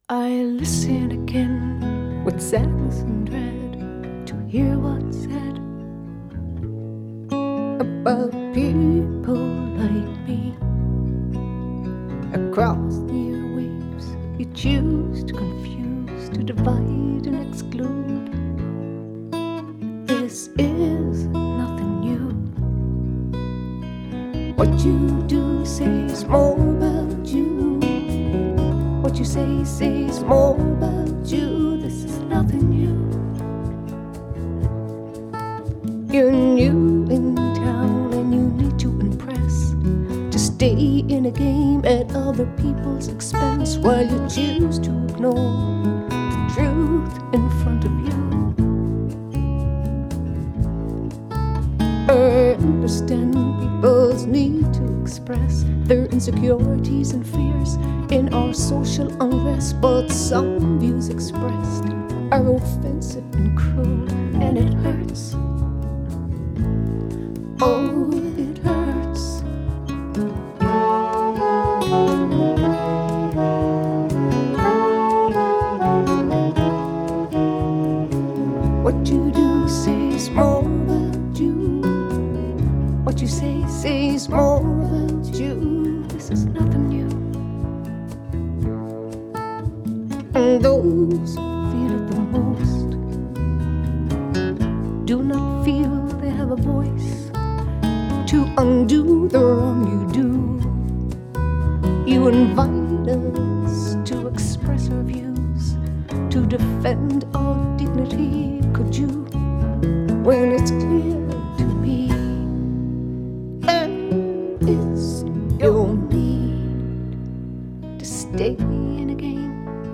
Genre: Folk/Rock